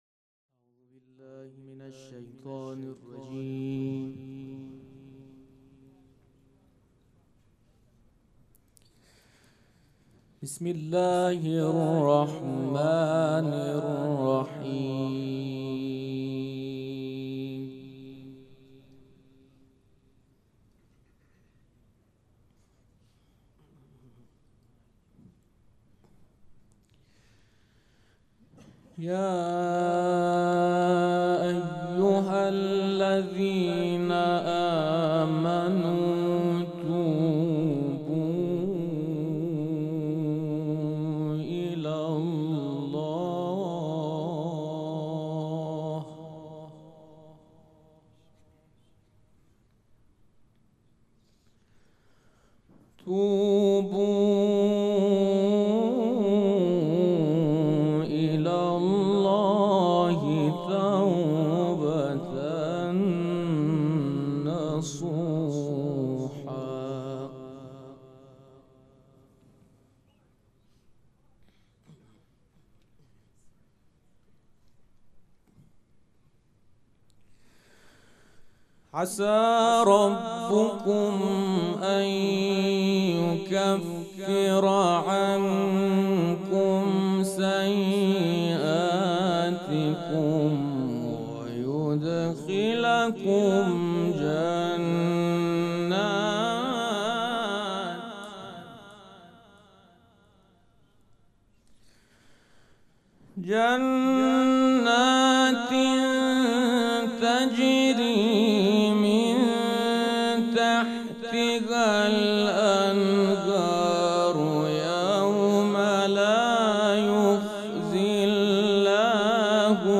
مراسم عزاداری شب دوم محرم الحرام ۱۴۴۷
سبک اثــر قرائت قرآن